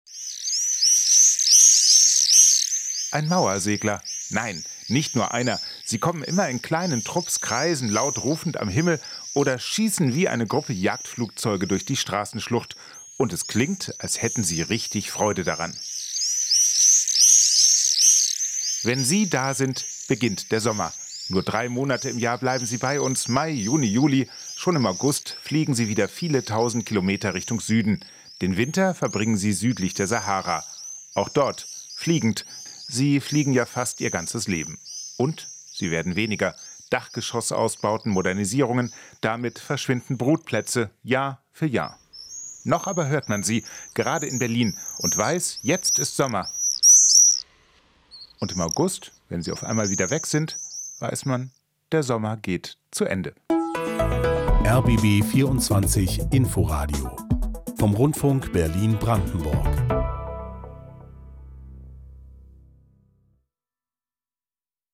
Geräusche, die verschwinden: Der Mauersegler